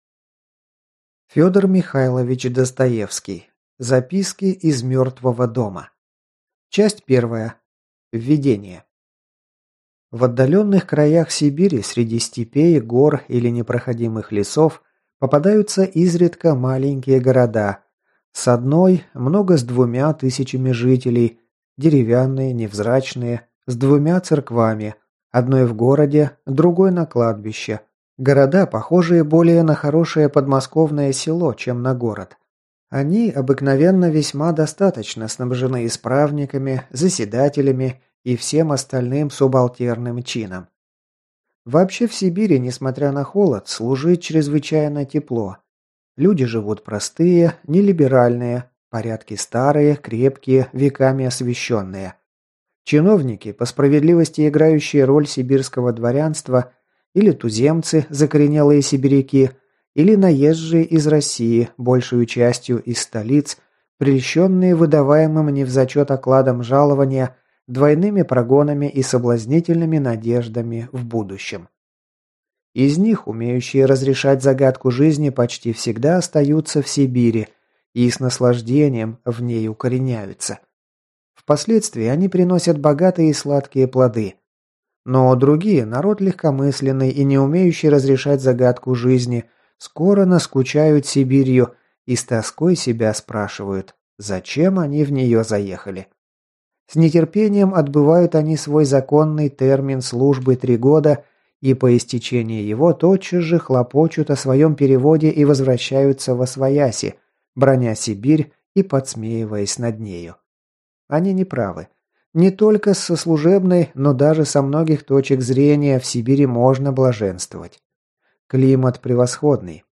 Аудиокнига Записки из мертвого дома | Библиотека аудиокниг